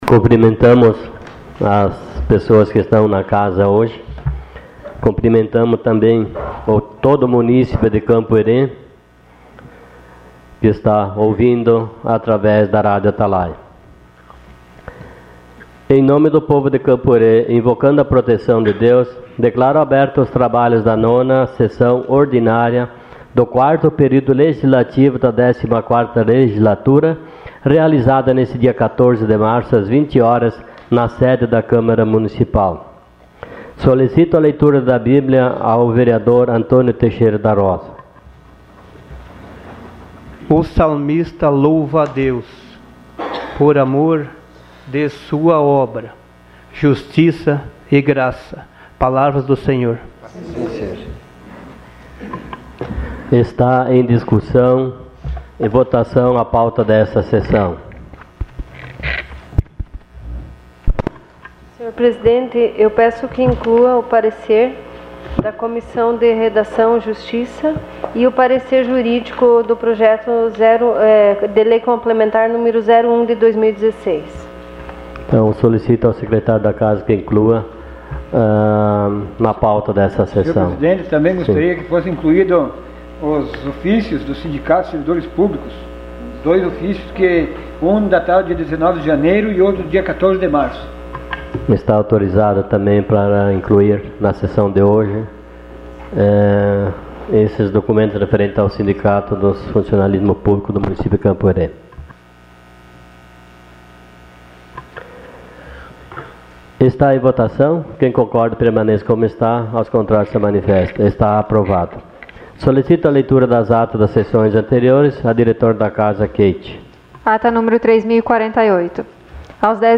Sessão Ordinária dia 14 de março de 2016.